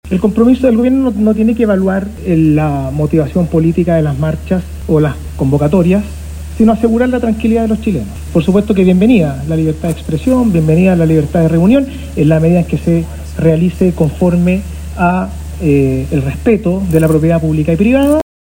En ese contexto, el subsecretario del Interior, Máximo Pávez, fue consultado por la reactivación de las marchas y si cree que esto responde a que son un gobierno de derecha. Al respecto, Pávez sostuvo que, más allá de las motivaciones políticas, su labor -como Ejecutivo- estará centrada en asegurar el normal funcionamiento de la ciudad.